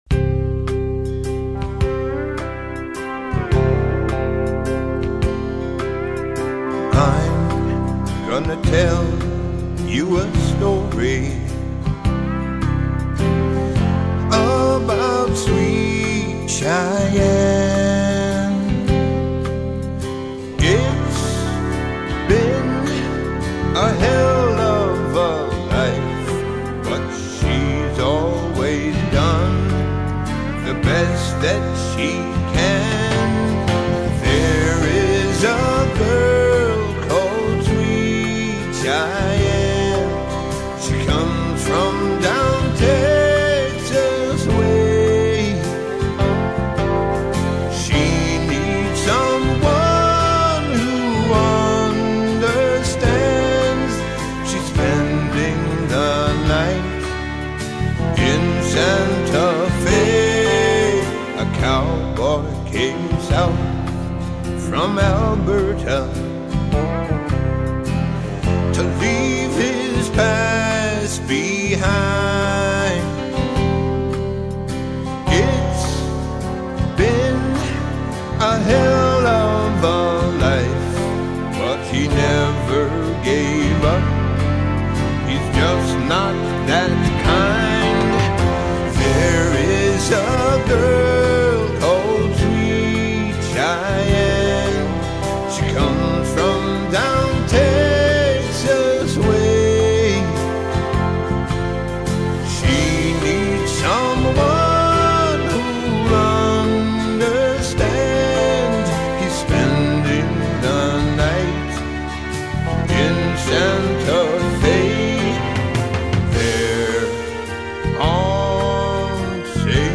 Here is one of my own Caribilly influenced songs